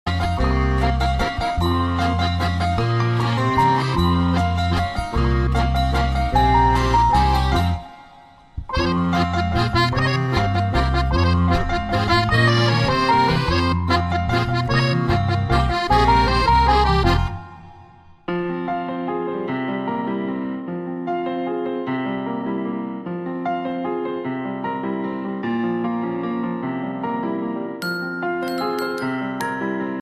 Suoneria new cartoon musical effect
Sound Effects